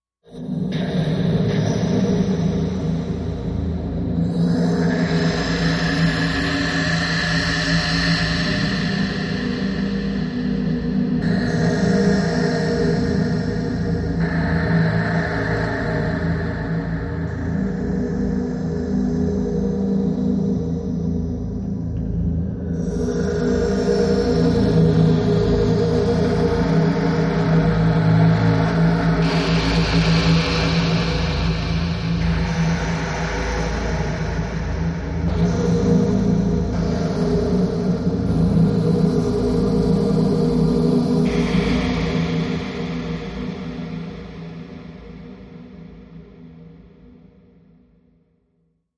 Liquid Fear Distant Waves Medium Hollow Wind Echoes